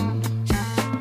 Sedan den som låter trasig:
trasig.wav